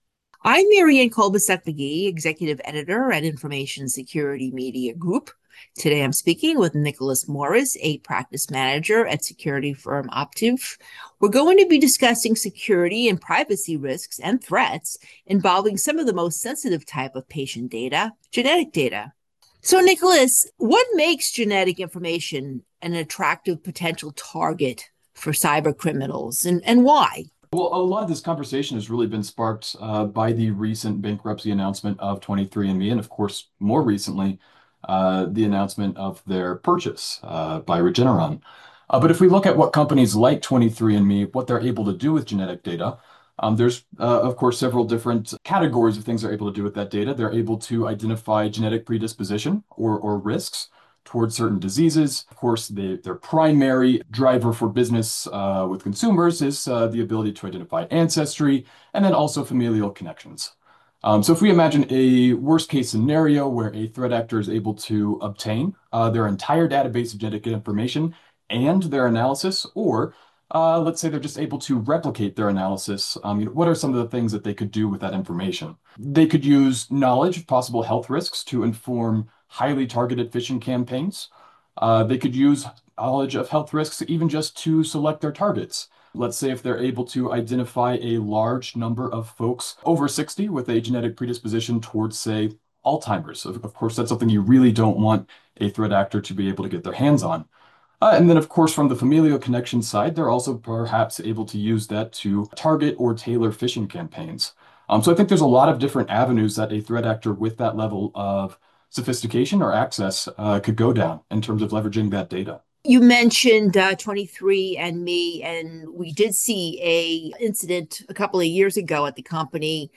Exclusive, insightful audio interviews by our staff with info risk/security leading practitioners and thought-leaders
Audio interviews with information security professionals.